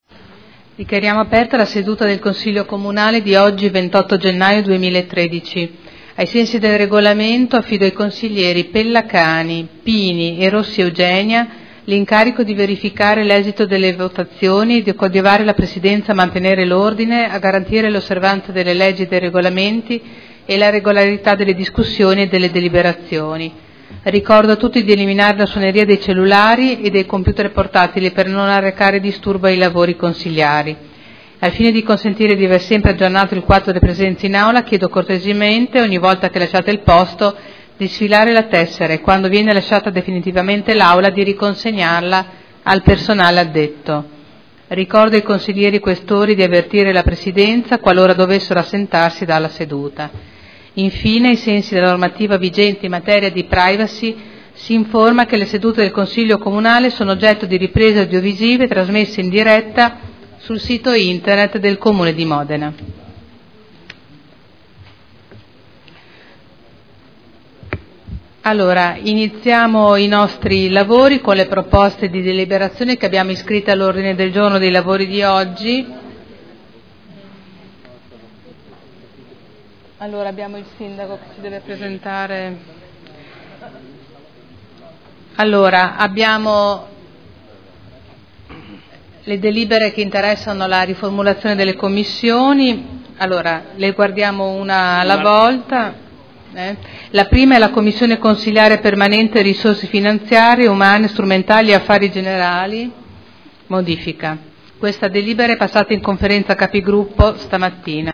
Seduta del 28/01/2013. Il Presidente Caterina Liotti apre i lavori del Consiglio.